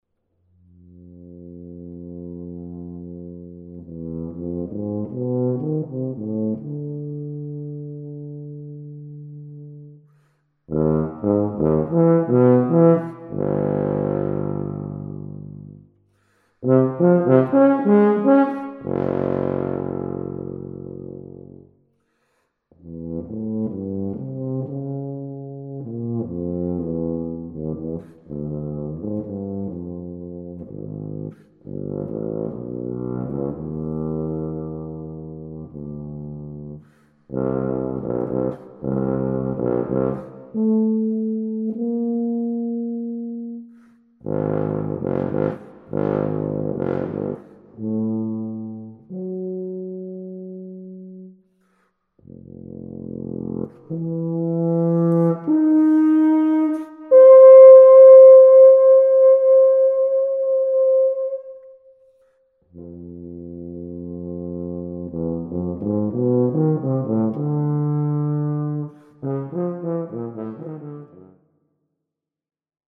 Voicing: Tuba